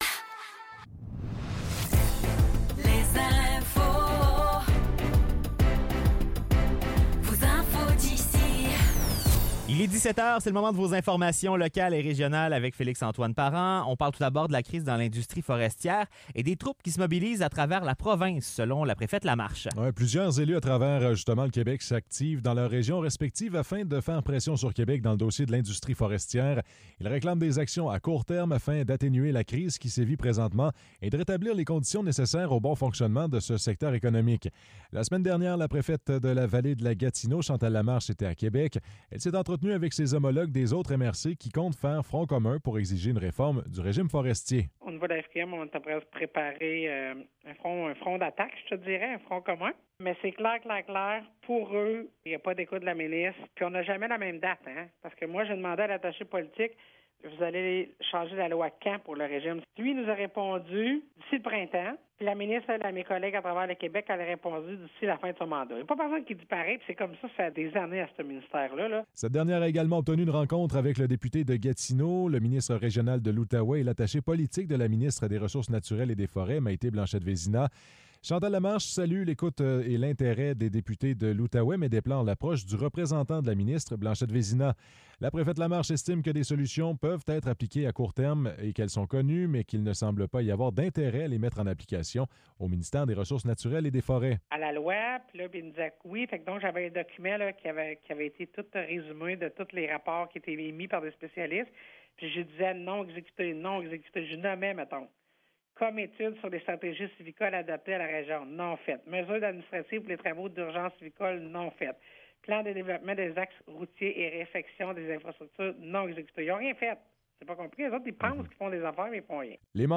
Nouvelles locales - 31 octobre 2024 - 17 h